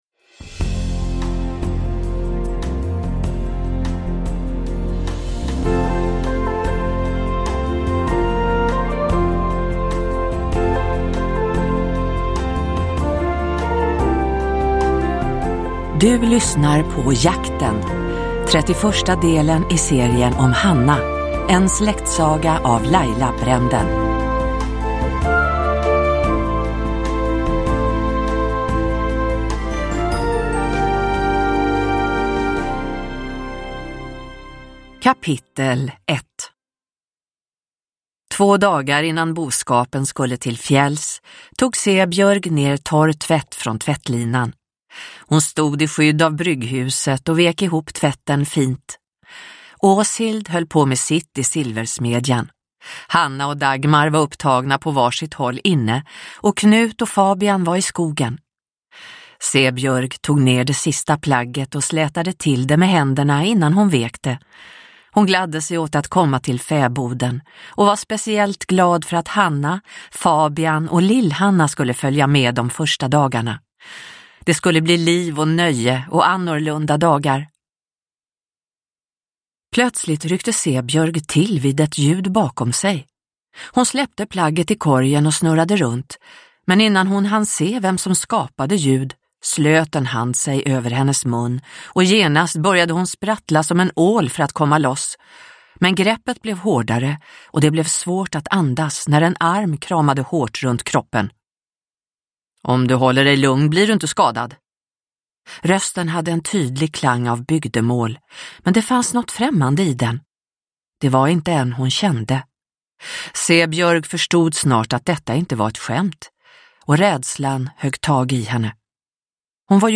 Jakten – Ljudbok – Laddas ner